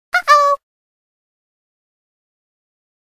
ICQの通知音